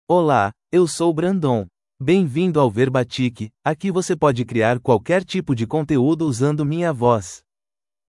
MalePortuguese (Brazil)
BrandonMale Portuguese AI voice
Voice sample
Male